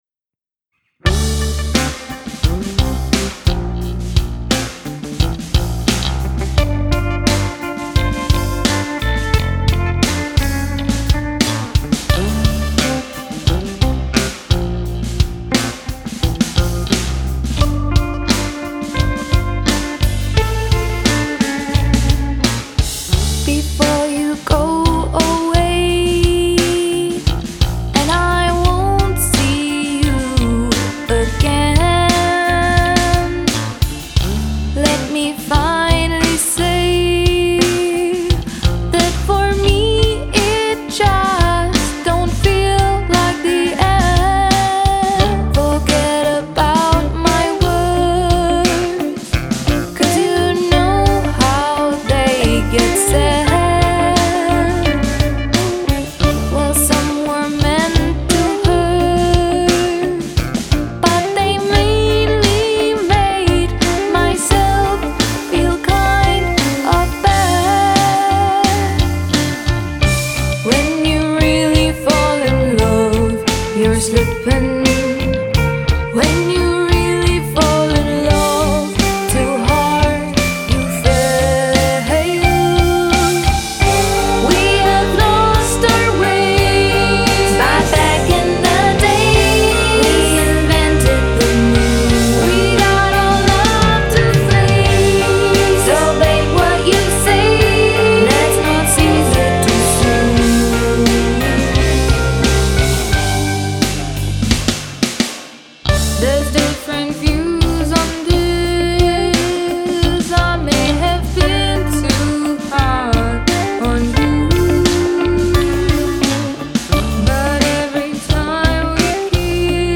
Das Intro musste irgendwie doch wieder weichen.
Es bleibt vorerst eine Arbeitsversion mit fertiger Struktur